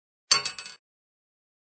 Descarga de Sonidos mp3 Gratis: caida bala.